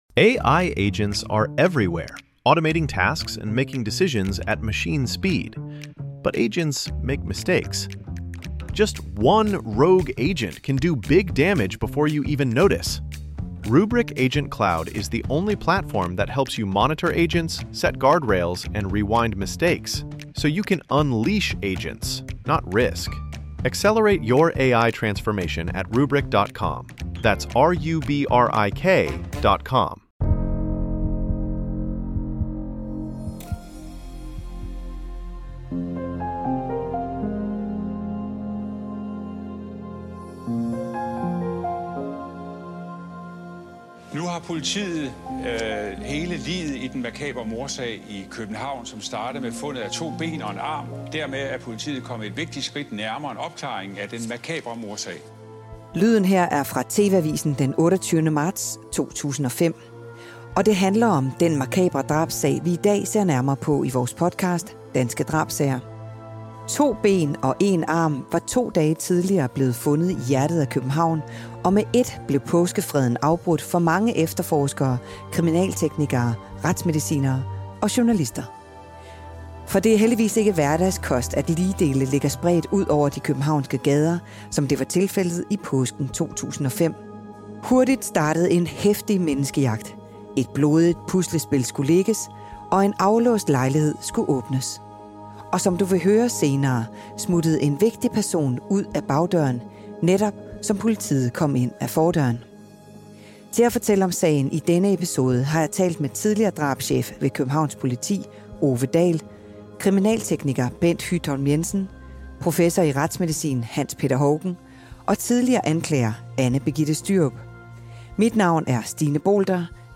retsmediciner
kriminaltekniker
Vært: Kriminalreporter